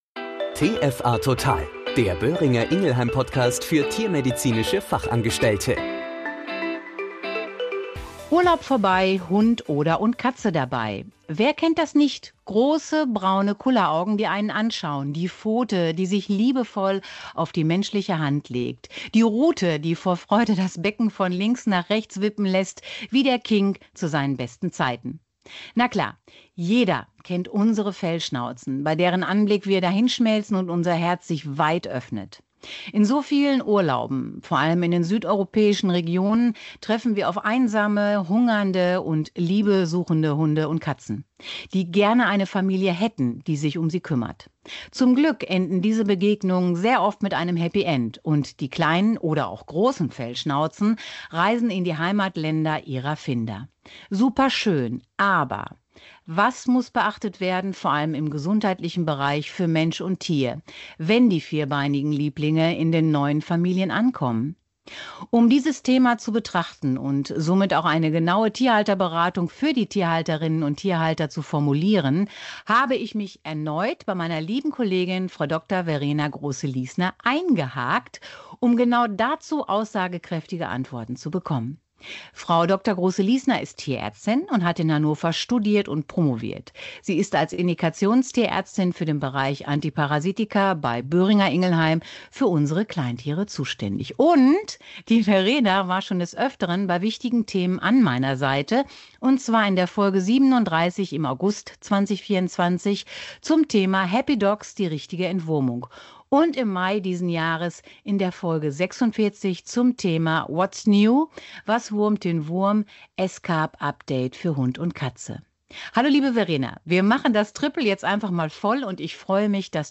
In diesem Interview mit einer erfahrenen Tierärztin soll die Tierhalterberatung maßgeblich unterstützt werden.